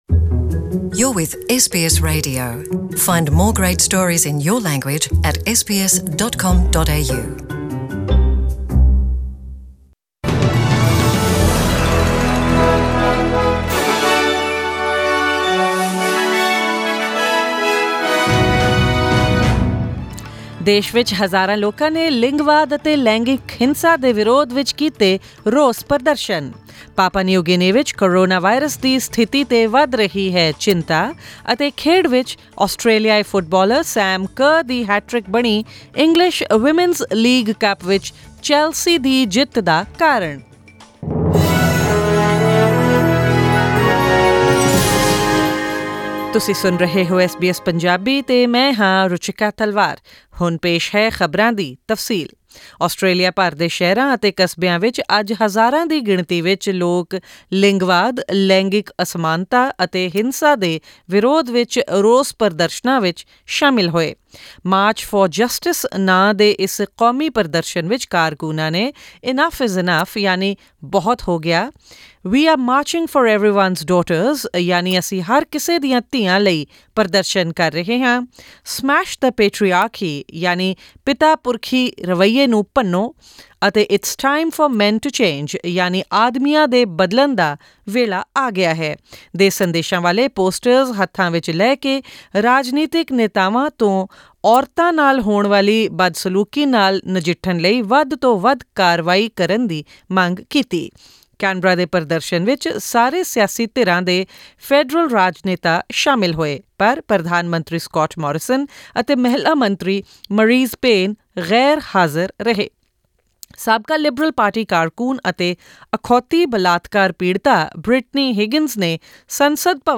Click the audio icon in the photo above to listen to the full news bulletin in Punjabi.